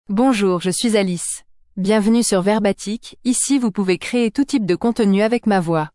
AliceFemale French AI voice
Alice is a female AI voice for French (France).
Voice sample
Listen to Alice's female French voice.
Alice delivers clear pronunciation with authentic France French intonation, making your content sound professionally produced.